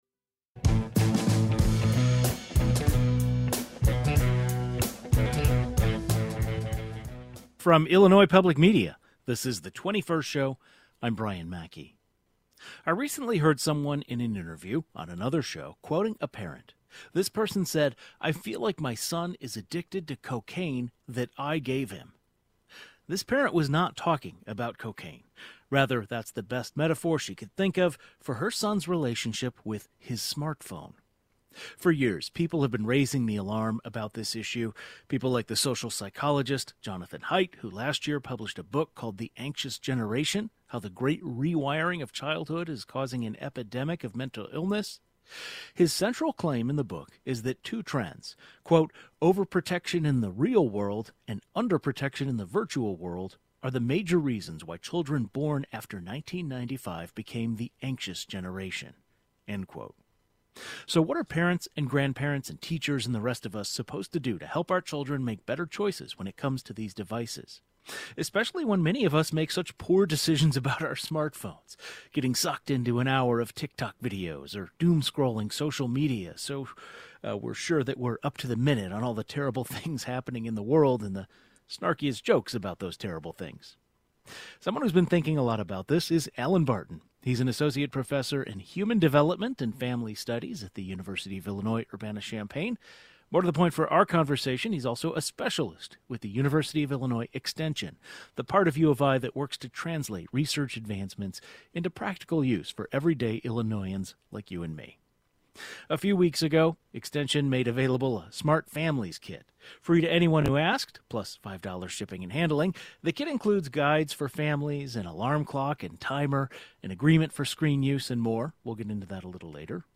An expert who specializes in human developement and family studies gives his take on smartphones and families.